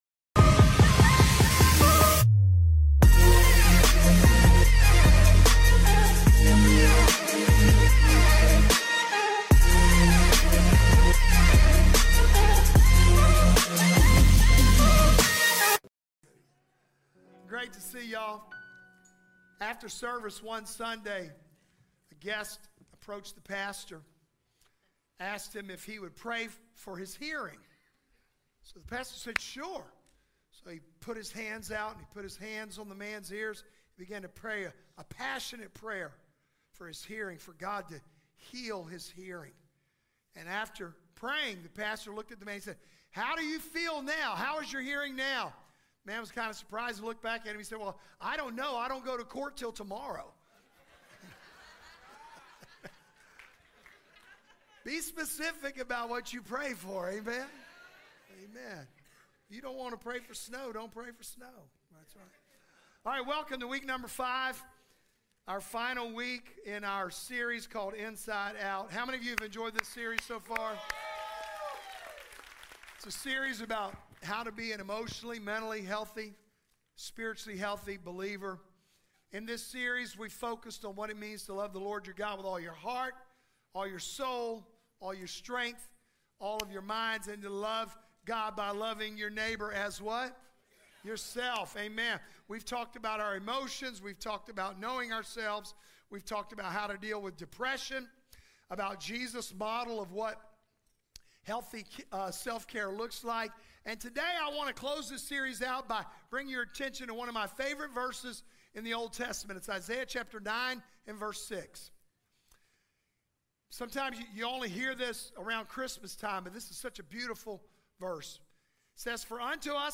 The sermon unpacks three compelling reasons why we need counsel: we don't know what we don't know, there's safety in wise advisors, and counsel provides direction for growth.